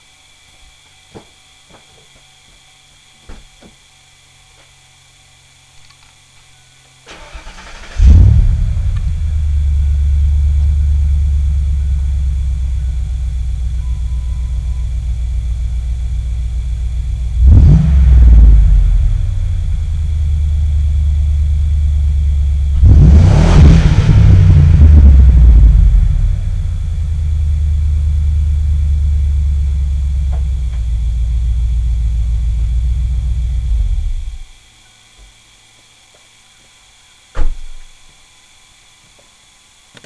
Listen to my 3" Magnaflow exhaust Real audio Wave Pictures of the Ford Motorsports headers I installed Questions or comments?
explorer.wav